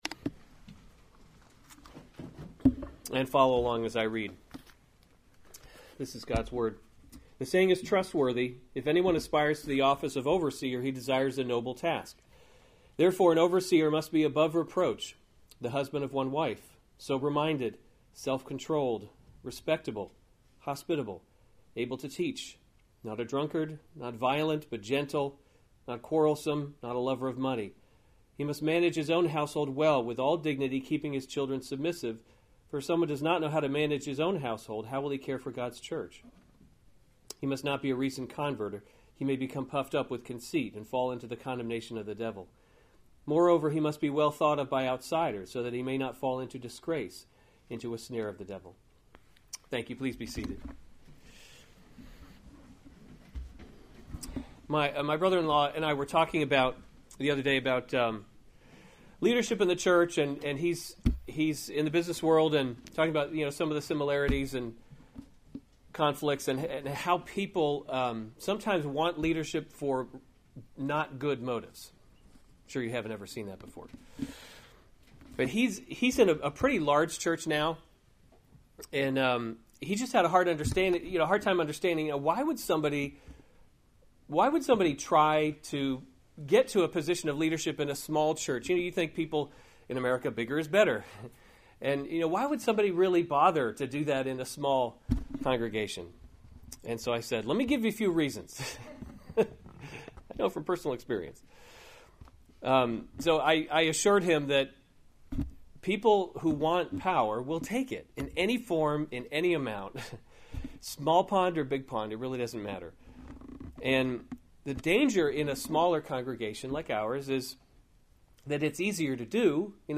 March 18, 2017 1 Timothy – Leading by Example series Weekly Sunday Service Save/Download this sermon 1 Timothy 3:1-7 Other sermons from 1 Timothy Qualifications for Overseers 3:1 The saying is […]